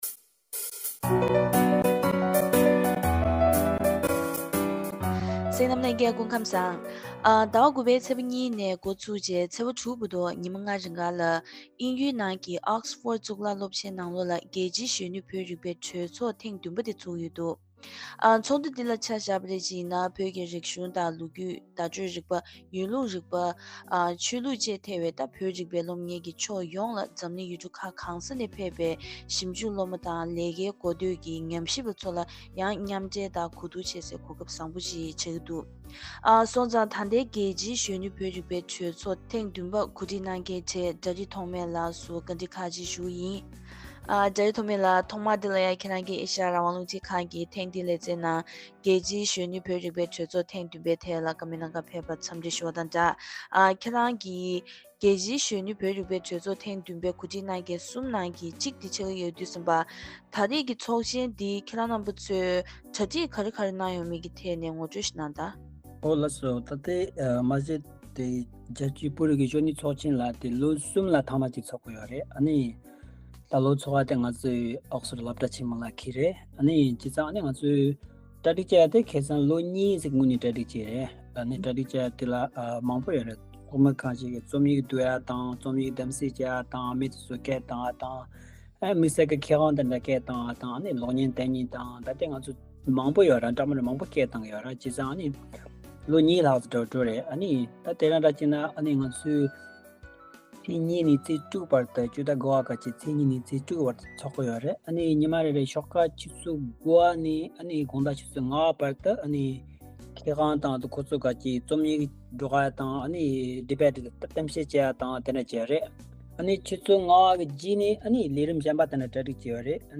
བཀའ་དྲི་ཞུས་པ་ཞིག་གཤམ་ལ་གསན་གནང་གི་རེད།